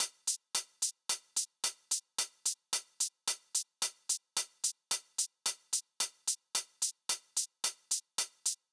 13 Hihat.wav